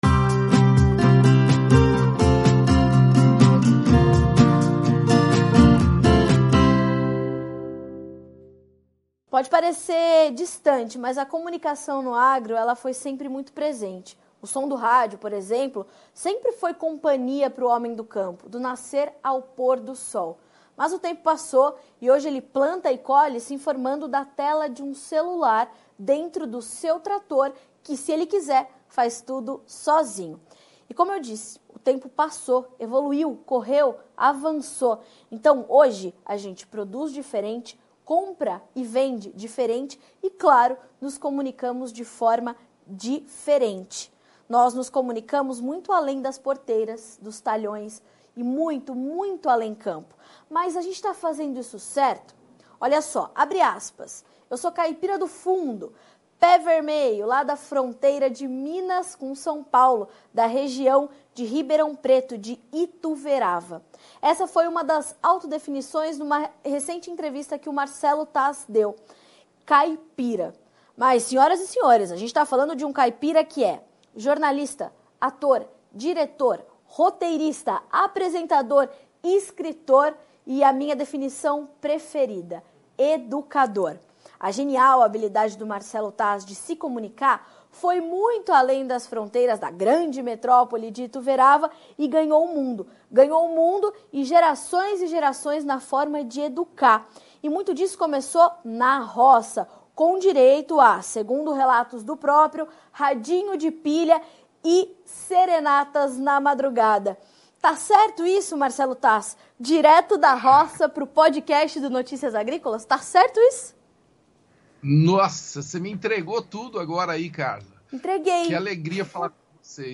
Entrevista com Marcelo Tas - Jornalista